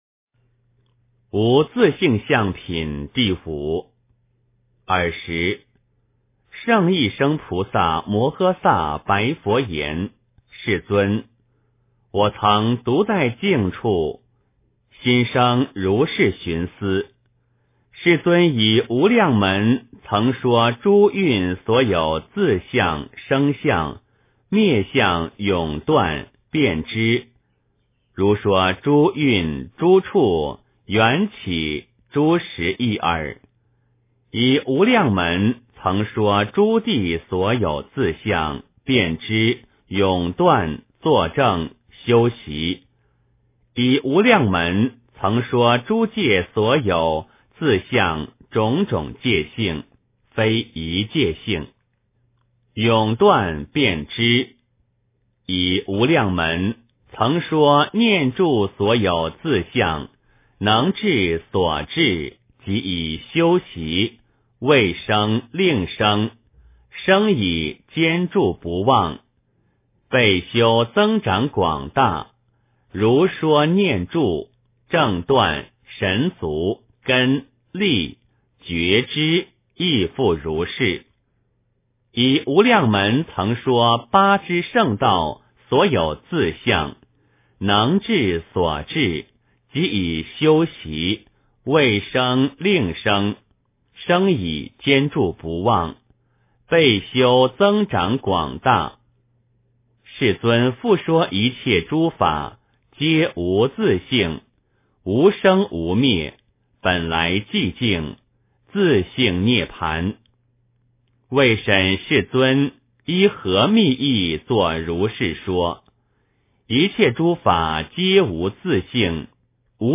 解深密经-5（念诵） 诵经 解深密经-5（念诵）--未知 点我： 标签: 佛音 诵经 佛教音乐 返回列表 上一篇： 解深密经-1（念诵） 下一篇： 解深密经-6（念诵） 相关文章 天心月圆--龚玥 天心月圆--龚玥...